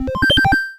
retro_beeps_collect_item_02.wav